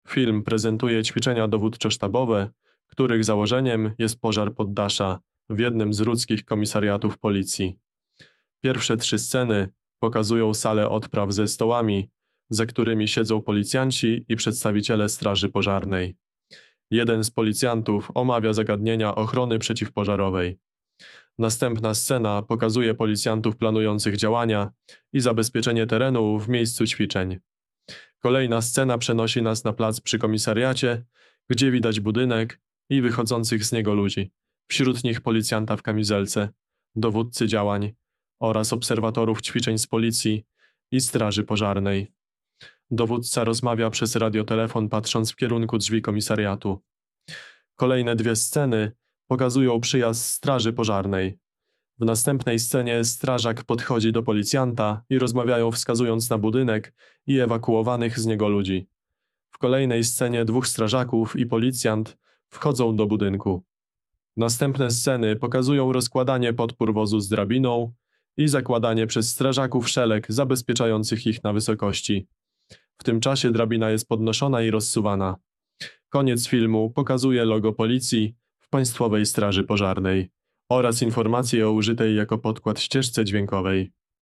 Nagranie audio audiodeskrypcja
Opis nagrania: plik z nagraniem głosowym będącym audiodeskrypcją nagrania wideo